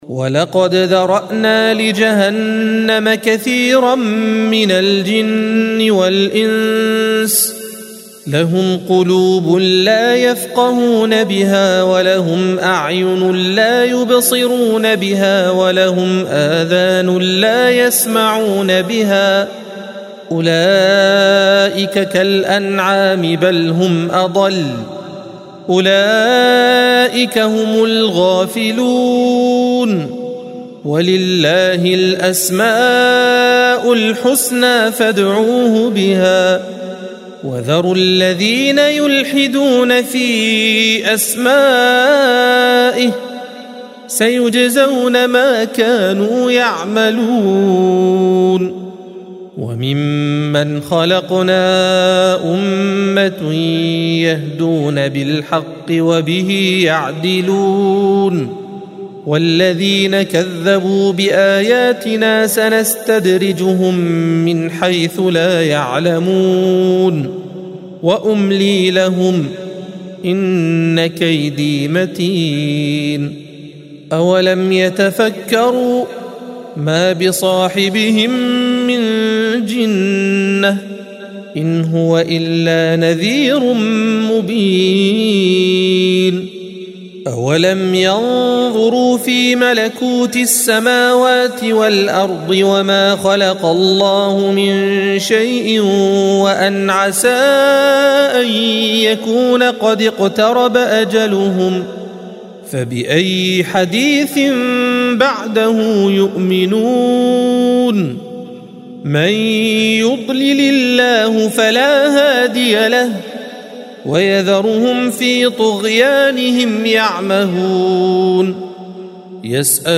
الصفحة 174 - القارئ